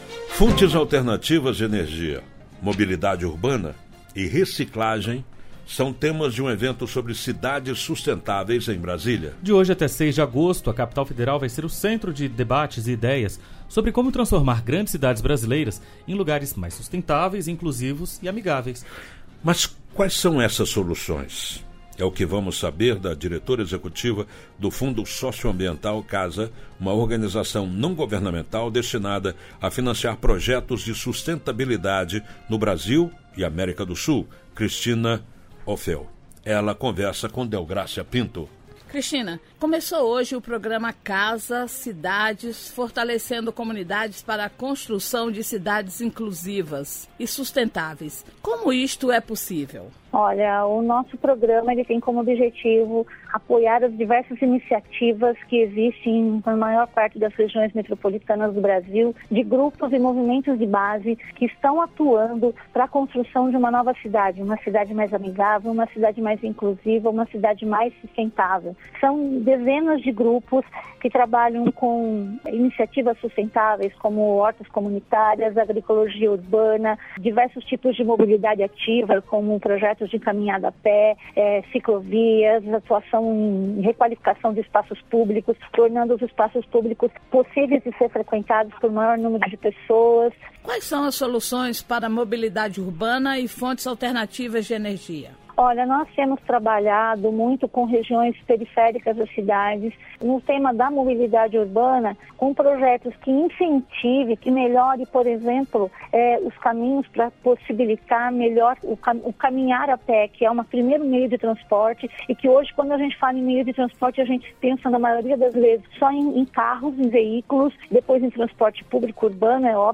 CASA Cidades na mídia – Entrevista Rádio Nacional